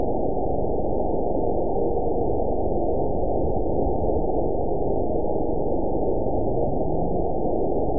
event 912032 date 03/16/22 time 12:33:05 GMT (3 years, 2 months ago) score 8.34 location TSS-AB05 detected by nrw target species NRW annotations +NRW Spectrogram: Frequency (kHz) vs. Time (s) audio not available .wav